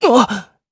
Zafir-Vox_Damage_jp_01.wav